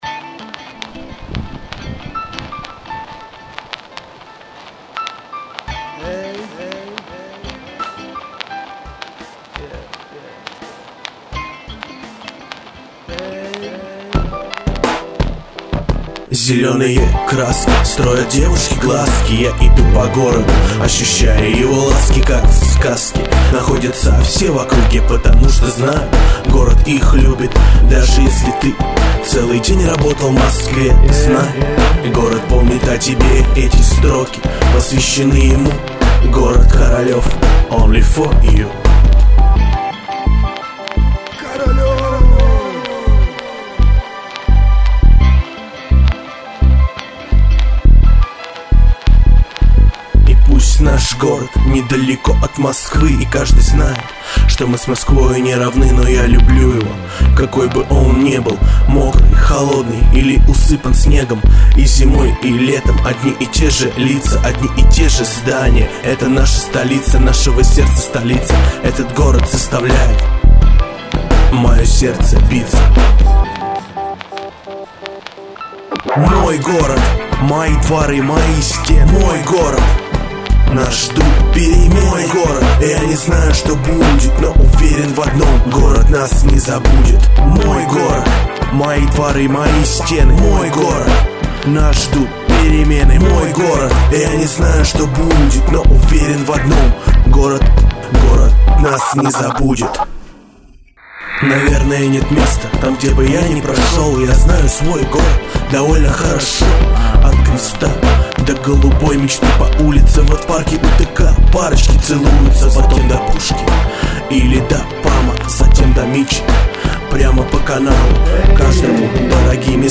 Песня
Качество записи не блеск, к сожалению.